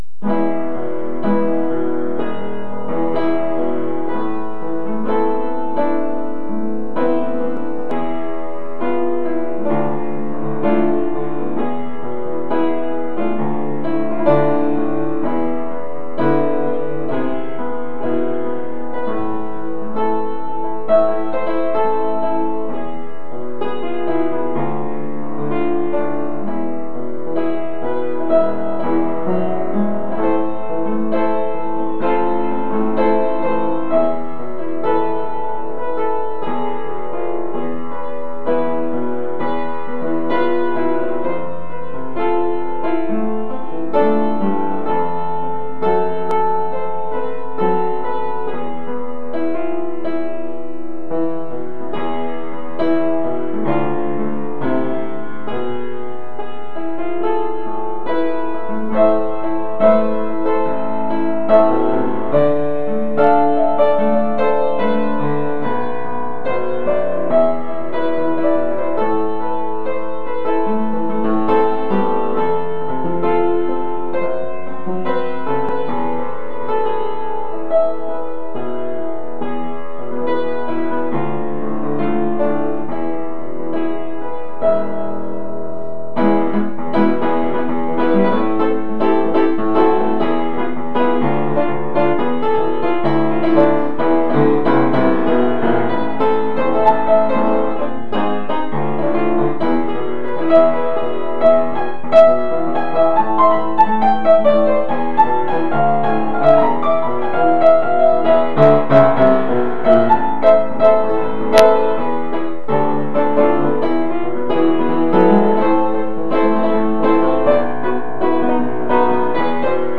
החלק המהיר מבולגן בעייני מידי.